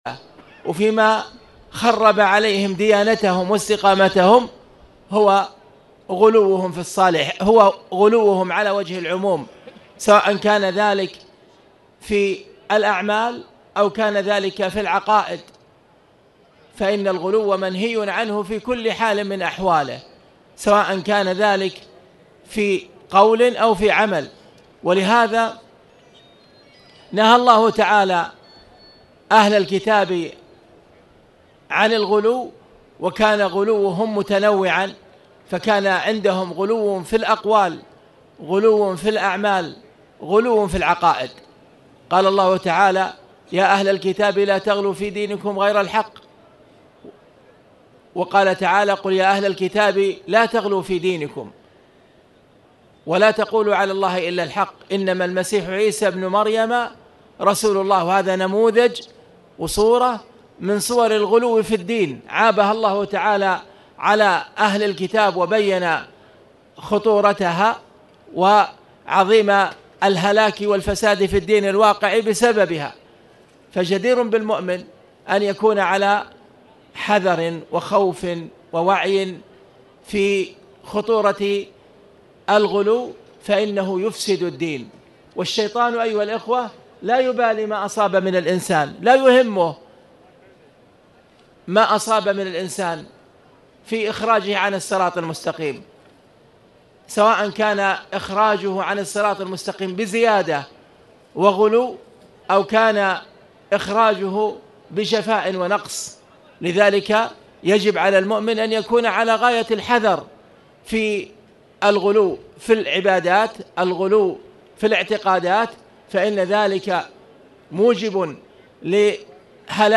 تاريخ النشر ١ جمادى الأولى ١٤٣٨ هـ المكان: المسجد الحرام الشيخ: خالد بن عبدالله المصلح خالد بن عبدالله المصلح باب ما جاء في التغليظ فيمن عبدالله عند قبر رجل صالح